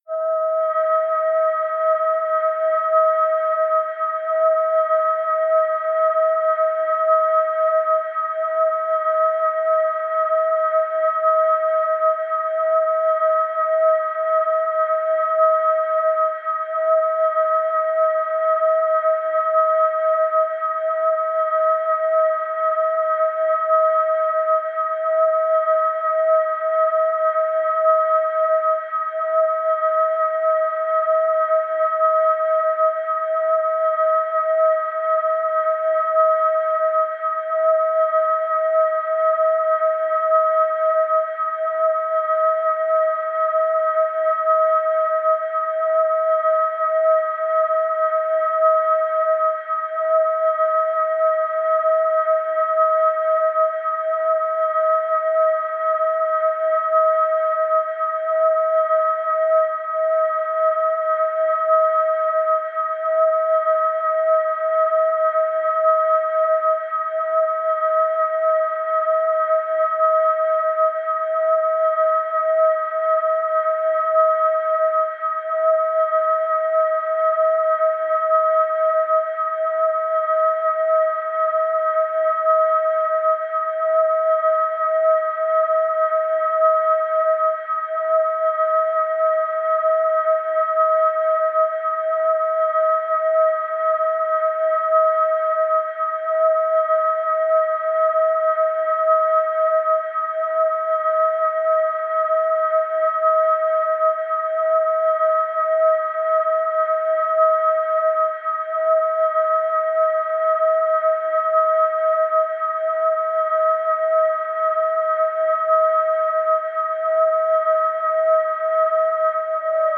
The Assemblage Point Frequencies